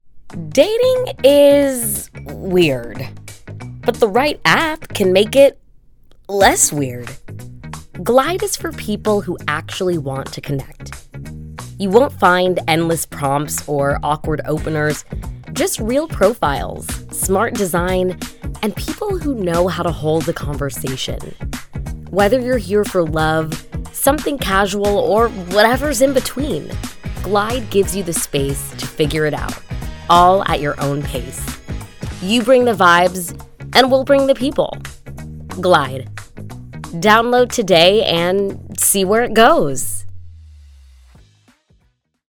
Warm, polished, and engaging voiceover talent with a background in theater & law
Commercial - Dating App, Millenial / Gen Z, Advertisement, Podcast
7 Glide Dating App 2 (FINAL with music).mp3